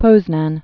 (pōznăn, -nän, pôznänyə)